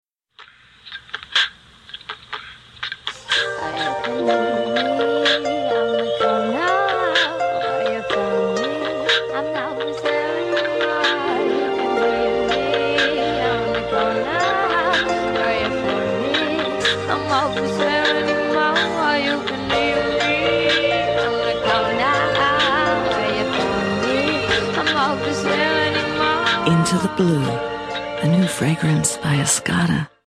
Sprecherin amerikanisch englisch für Werbung, Sport, Kultur und Musiksendungen, Schulungsfilme, Dokumentationen, PC-Spiele, Zeichentrickfilme
middle west
Sprechprobe: Industrie (Muttersprache):